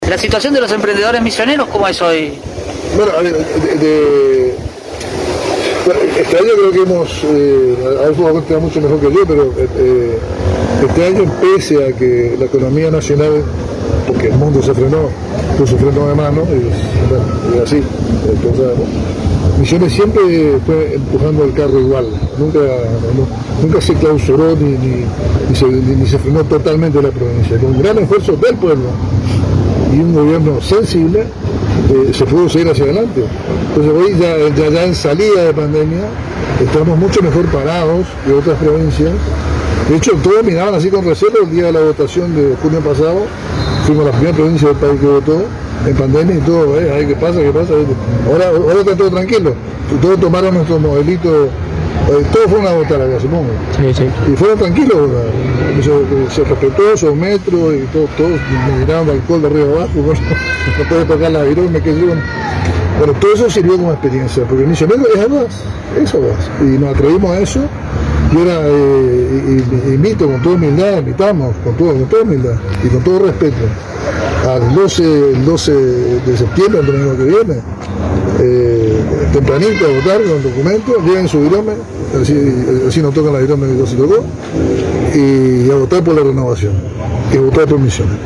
En charla con Radio Elemental